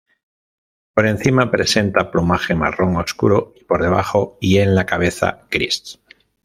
Pronounced as (IPA)
/pluˈmaxe/